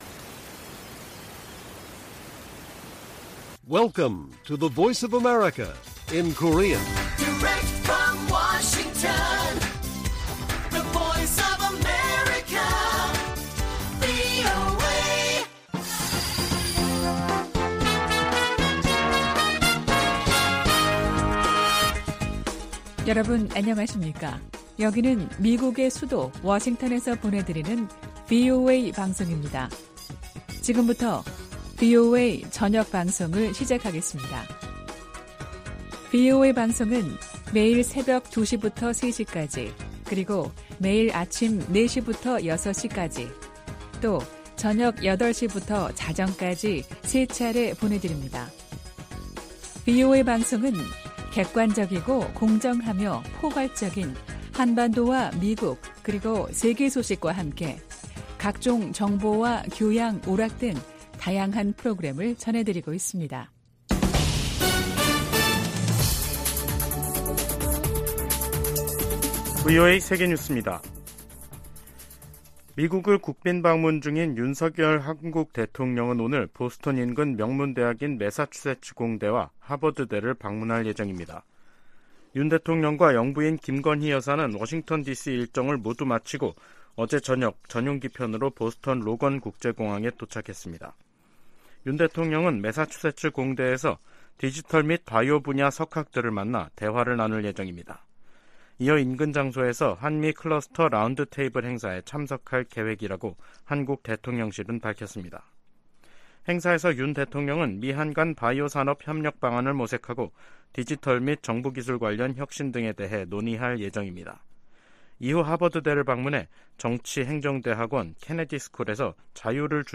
VOA 한국어 간판 뉴스 프로그램 '뉴스 투데이', 2023년 4월 28일 1부 방송입니다. 윤석열 한국 대통령은 27일 미 상·하원 합동회의 연설에서 북한 도발에 단호히 대응할 것이라며 미한 공조와 미한일 협력의 중요성을 강조했습니다. 국무부 오찬에 참석한 윤 대통령은 미한 동맹이 안보를 넘어 다양한 분야에서 강화되고 있다고 평가했습니다. 미국의 전문가들은 윤 대통령이 조 바이든 미국 대통령과 함께 동맹의 미래 청사진을 제시했다고 평가했습니다.